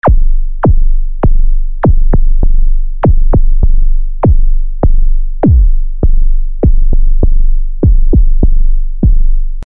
Deutlich umfangreicher ausgestattet ist der Sine Generator:
Hier habe ich die Sweep-Time des ersten Kraftpferdes im Stall (Preset „Powerhorse 1“) ziemlich hektisch moduliert, um einen lebendigen Attack zu erhalten.